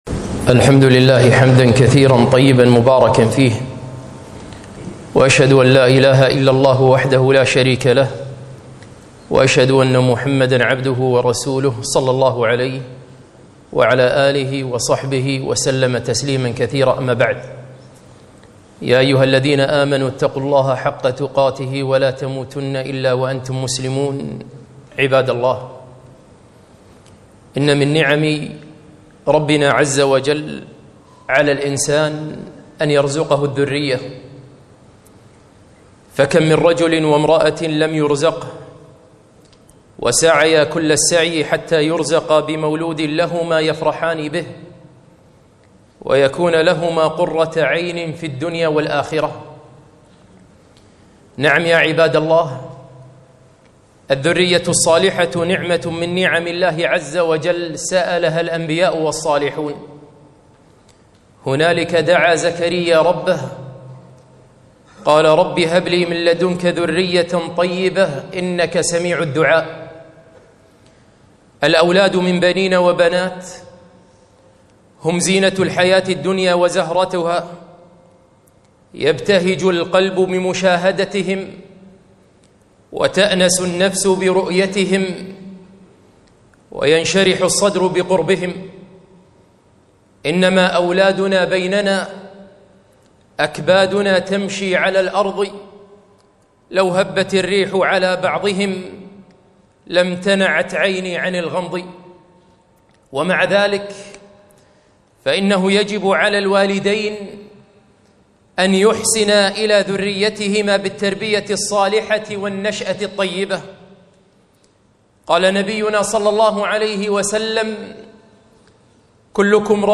خطبة - هل تركت أبنك يسافر معهم؟